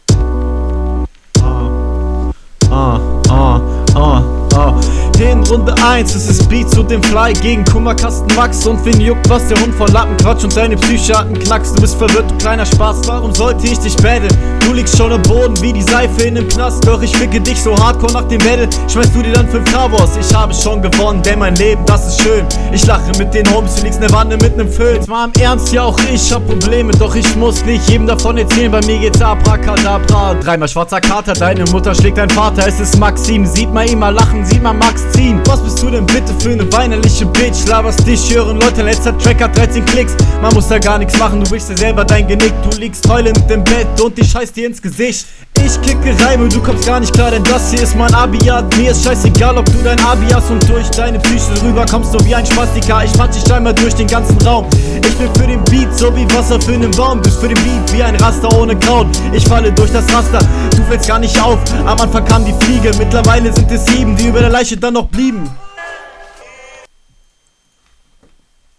Der Flow geht gut nach vorne, ist zwar zum teil offbeat, aber …
find deinen sound schonmal interessant. du rapst nice und das passt schön auf den beat.